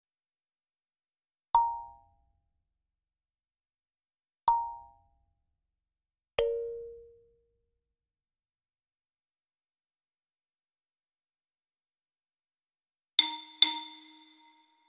دانلود آهنگ چشمک زدن 3 از افکت صوتی انسان و موجودات زنده
جلوه های صوتی
دانلود صدای چشمک زدن 3 از ساعد نیوز با لینک مستقیم و کیفیت بالا